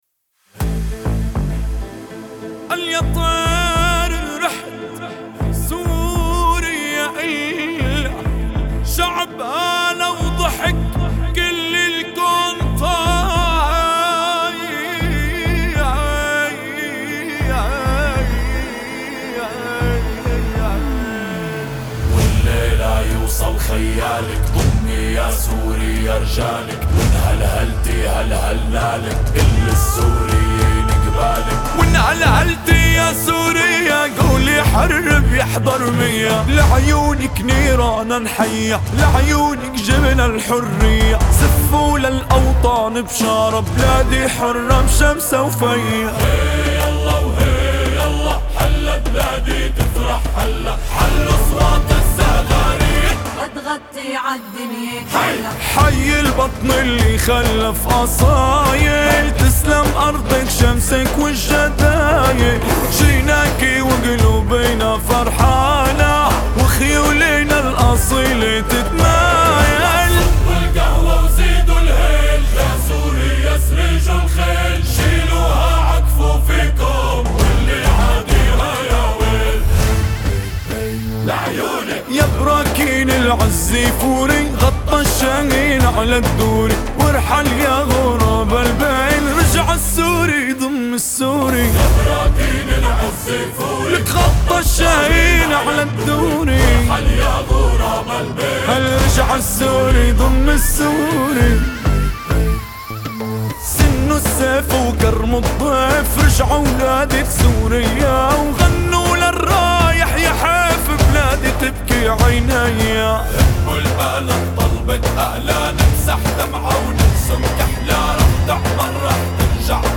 تفاصيل نشيد
ناي